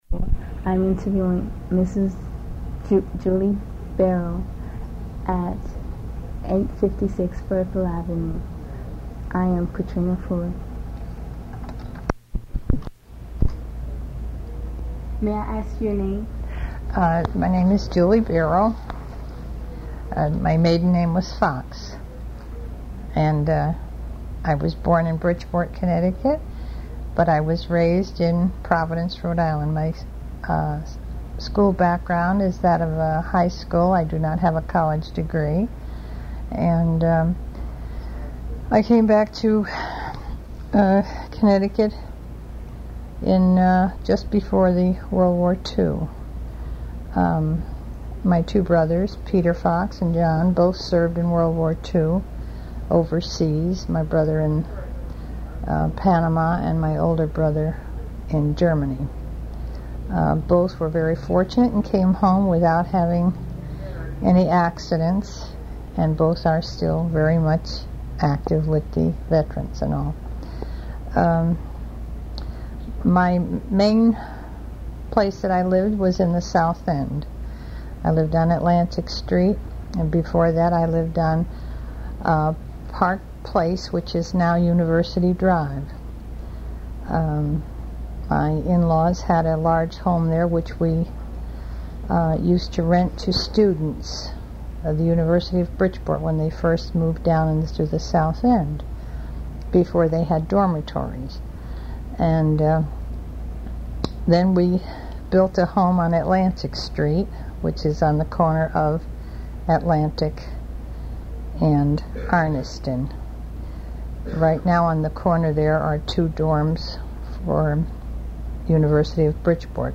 Bridgeport Living History : Oral Histories Conducted by Bridgeport Youth in 1984